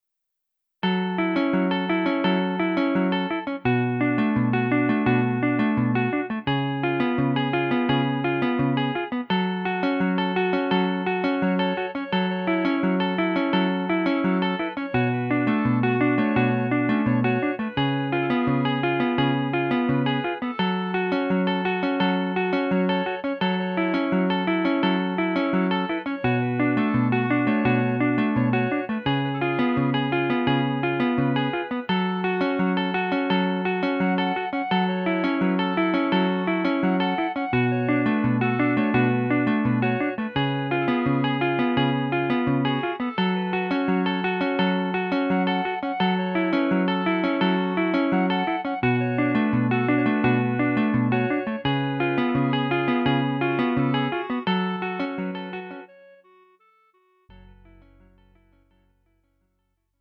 음정 -1키 3:26
장르 pop 구분 Lite MR